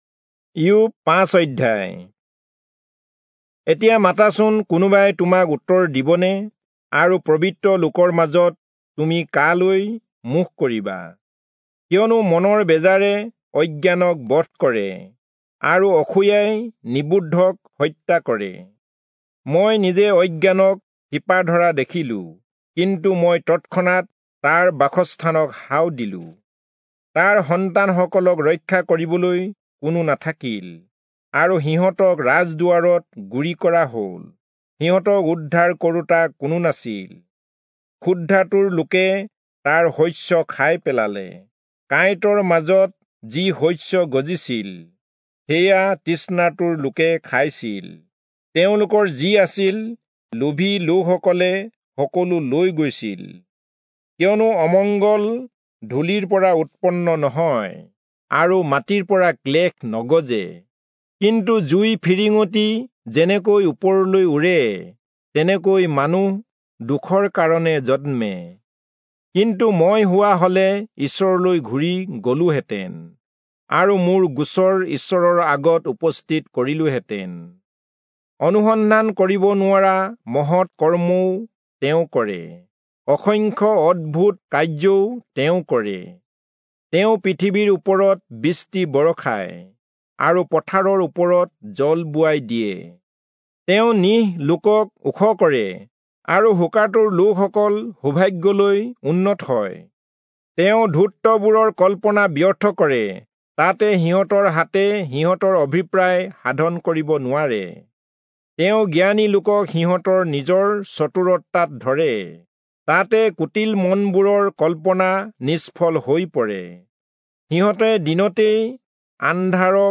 Assamese Audio Bible - Job 25 in Erven bible version